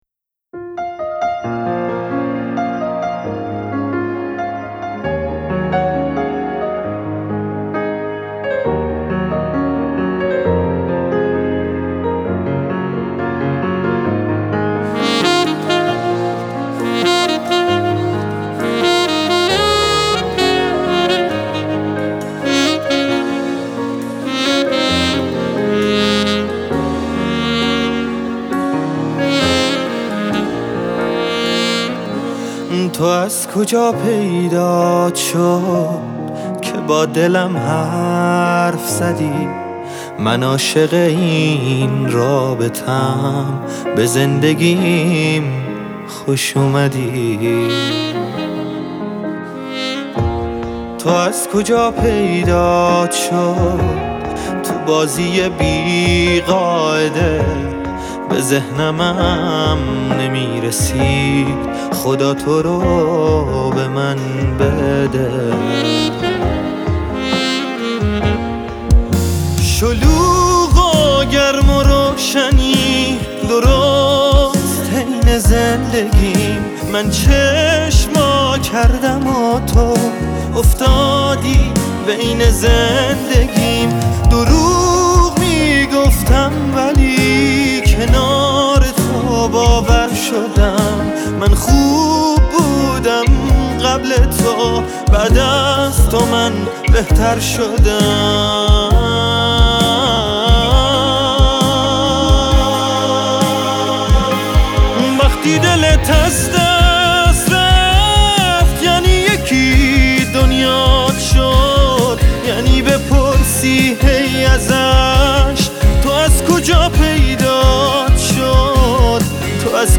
یک آهنگ عاشقانه